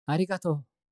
感謝 ボイス 声素材 – Gratitude Voice
Voiceボイス声素材